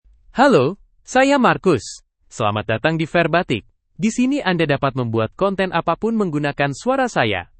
Marcus — Male Indonesian (Indonesia) AI Voice | TTS, Voice Cloning & Video | Verbatik AI
MarcusMale Indonesian AI voice
Marcus is a male AI voice for Indonesian (Indonesia).
Voice sample
Listen to Marcus's male Indonesian voice.
Marcus delivers clear pronunciation with authentic Indonesia Indonesian intonation, making your content sound professionally produced.